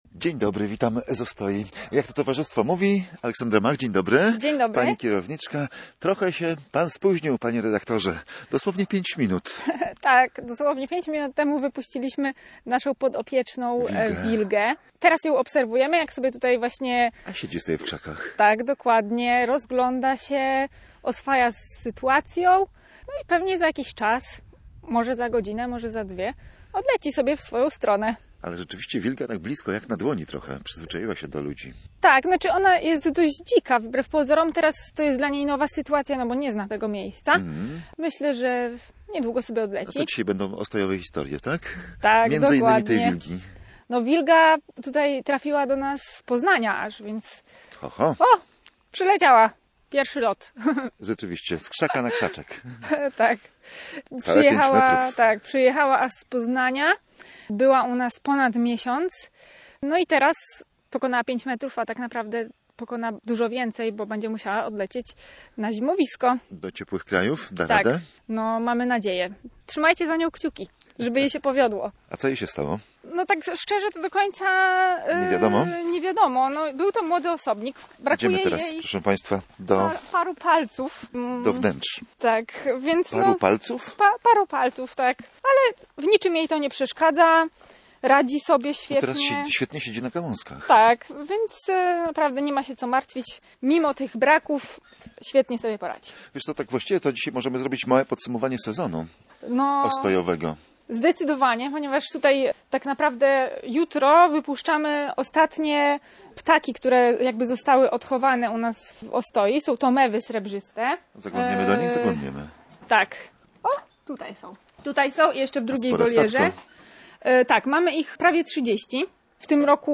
Gdy nasz reporter przyjechał do Ostoi właśnie wypuszczano na wolność wilgę.